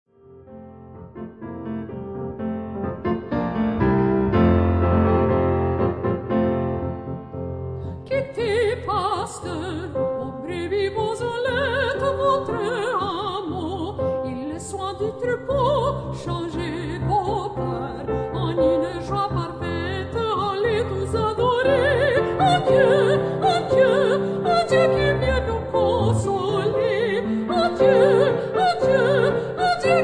French Christmas Carol
mezzo-soprano
pianist